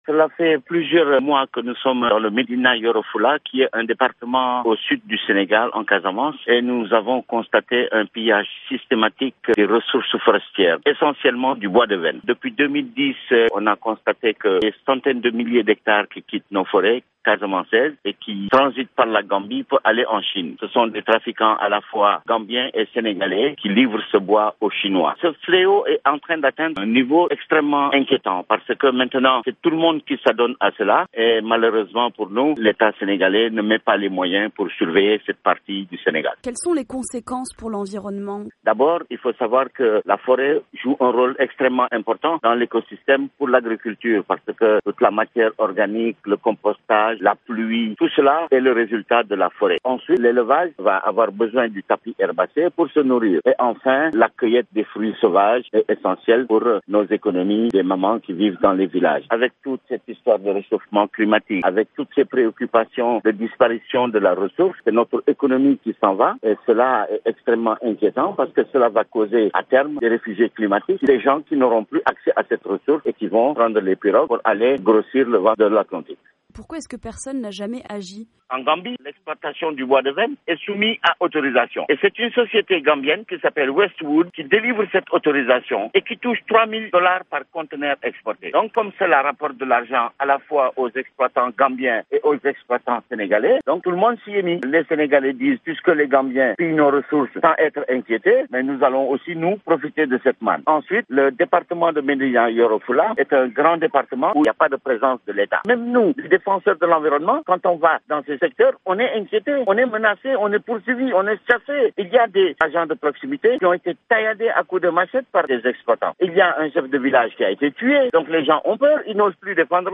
VOA Afrique a joint Haidar El Ali : "Tout ce bois qu'ils pillent, c'est le sang de mon pays qui est pillé par la Chine"